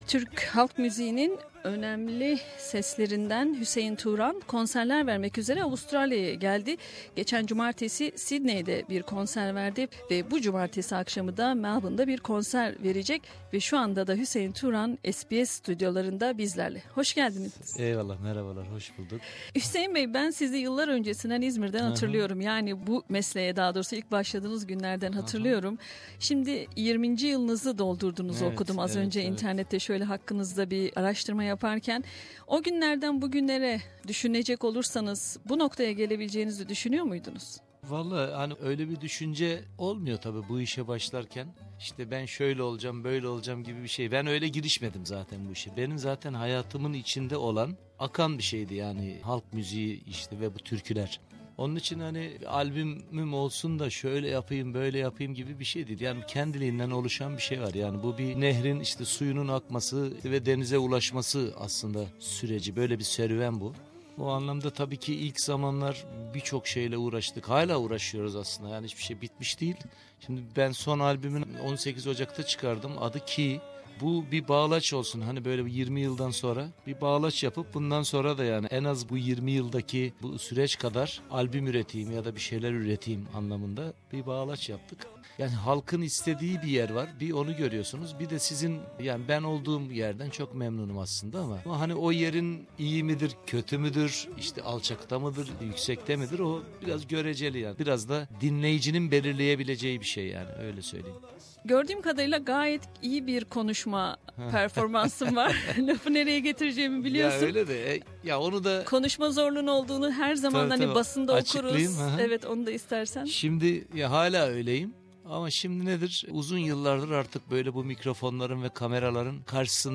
Interview
at SBS studios for an interview.